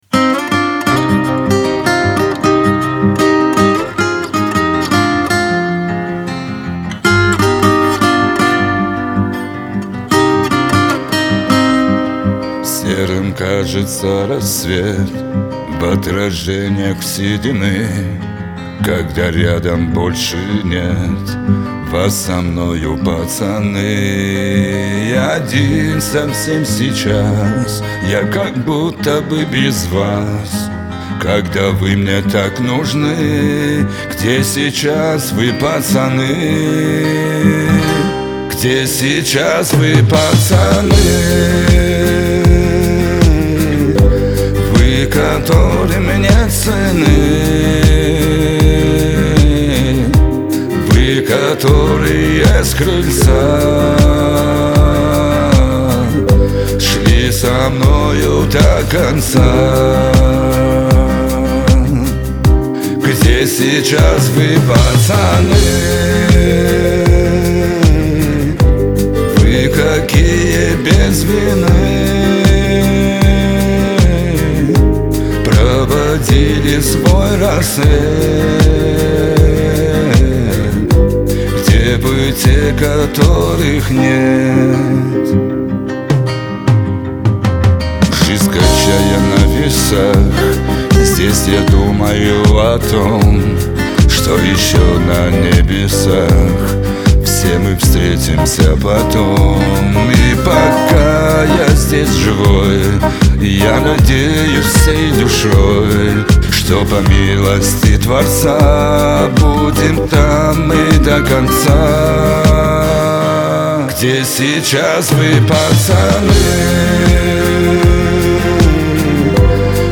Кавказ поп
грусть , Шансон